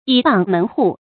倚傍門戶 注音： ㄧˇ ㄅㄤˋ ㄇㄣˊ ㄏㄨˋ 讀音讀法： 意思解釋： 謂依附、投靠某一門派或集團。